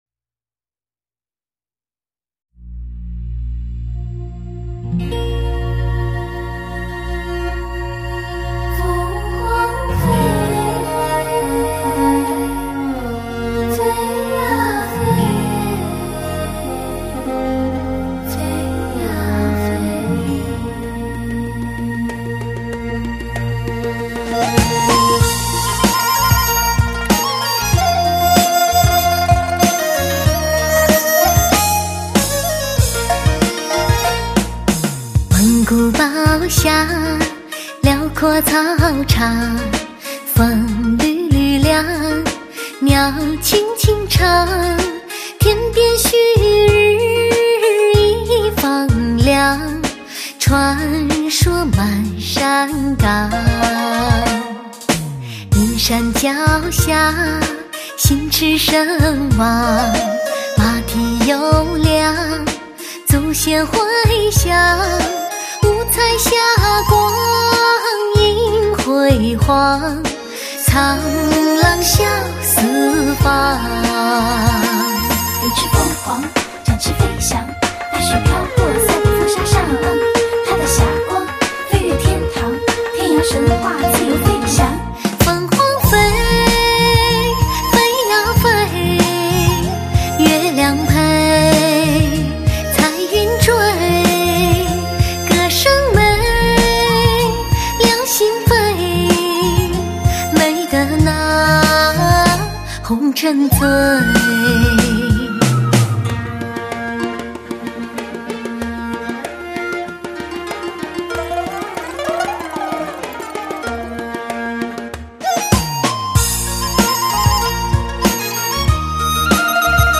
唱片类型：华语流行
唱片介绍: 注入诗情画意的东方风情，其韵扬扬悠悠，俨若行云流水